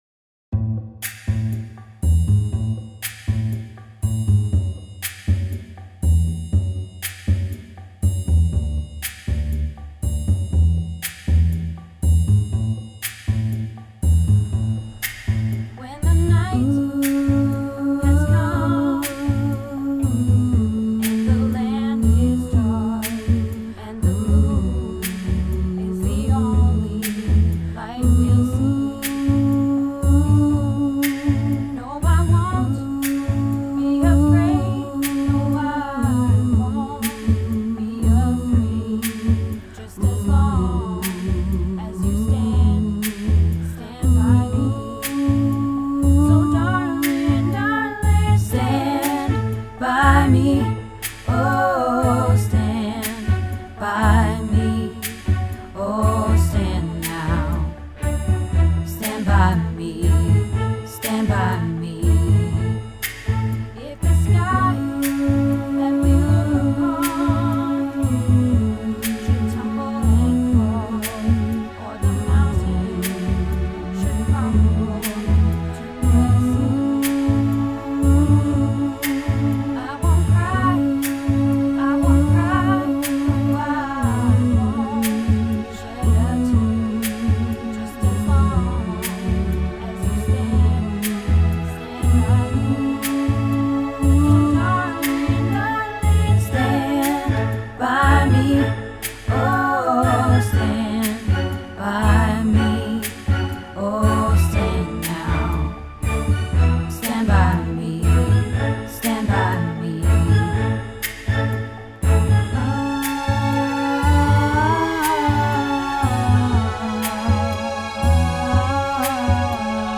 Stand By Me - Bass